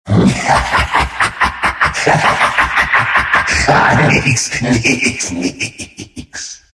Звуки дьявола, черта
Смех Люцифера и беса